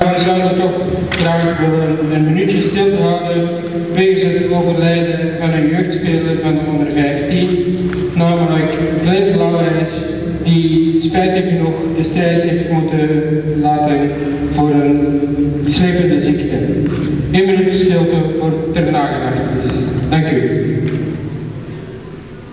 Minuut stilte